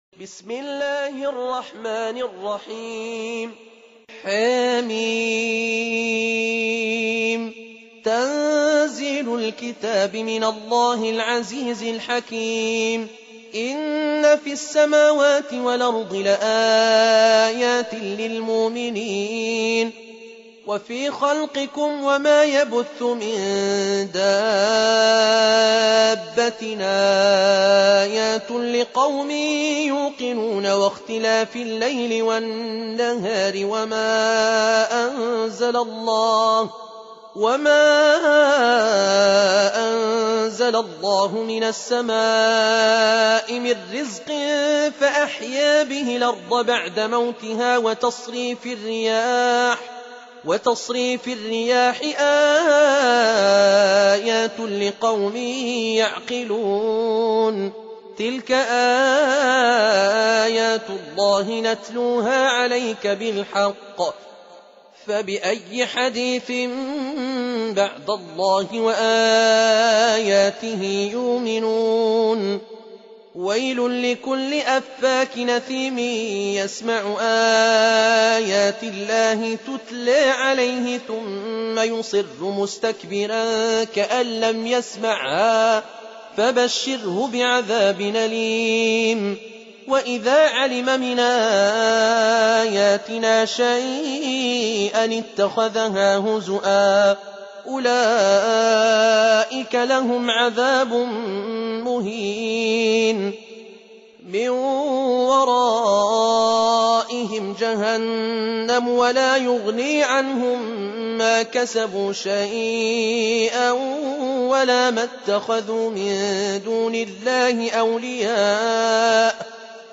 45. Surah Al-J�thiya سورة الجاثية Audio Quran Tarteel Recitation
Surah Sequence تتابع السورة Download Surah حمّل السورة Reciting Murattalah Audio for 45. Surah Al-J�thiya سورة الجاثية N.B *Surah Includes Al-Basmalah Reciters Sequents تتابع التلاوات Reciters Repeats تكرار التلاوات